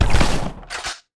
Sfx Icegun Shoot Sound Effect
sfx-icegun-shoot.mp3